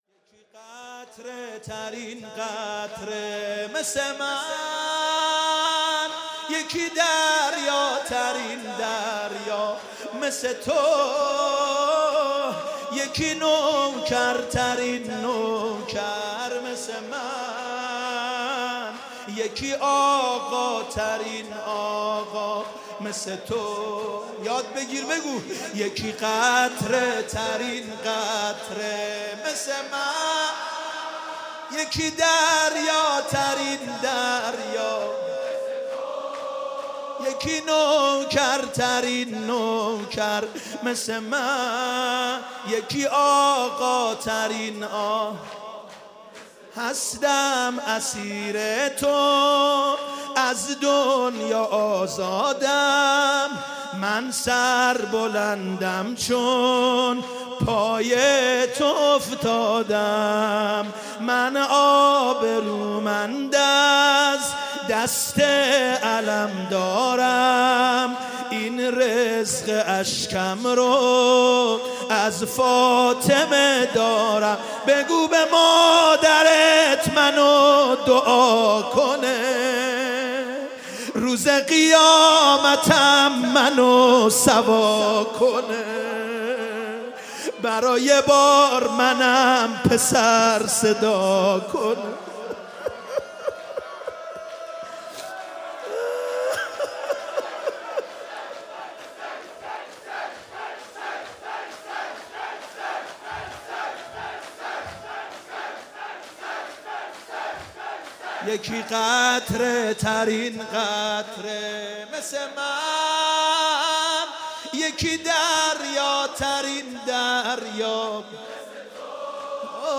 شب اول محرم - به نام نامی حضرت مسلم(ع)